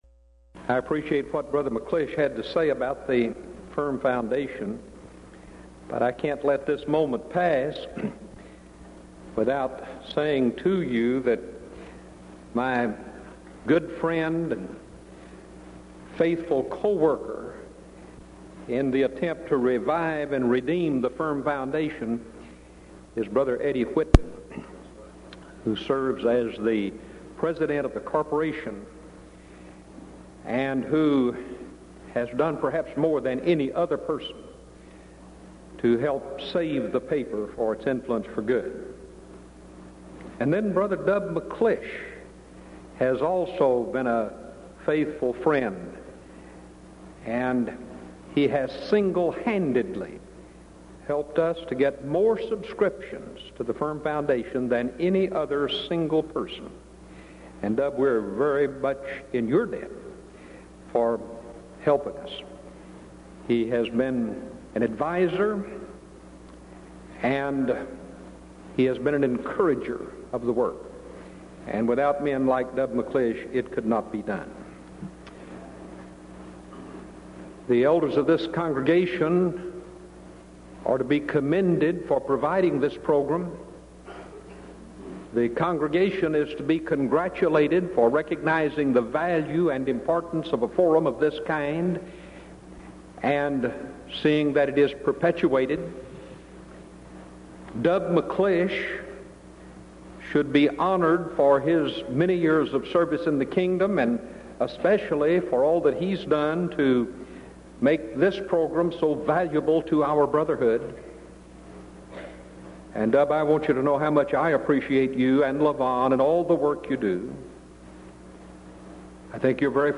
Event: 1992 Denton Lectures
lecture